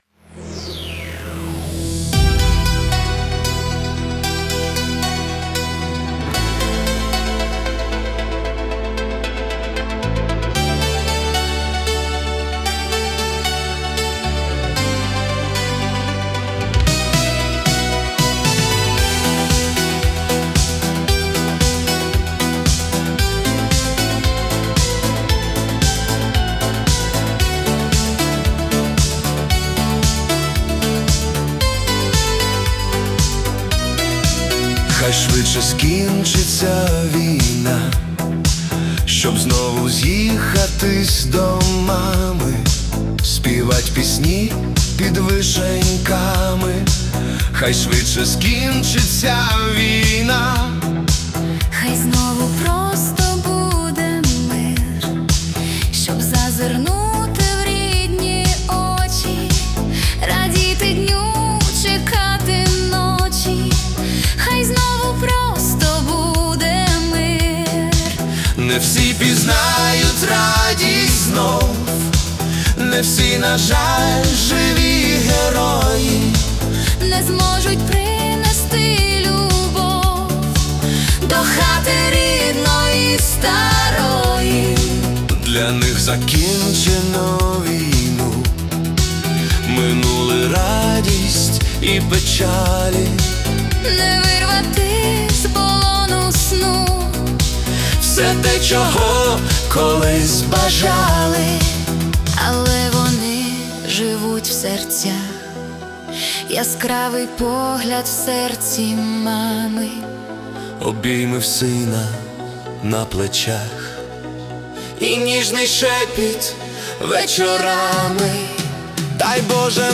🎵 Жанр: Spiritual Ballad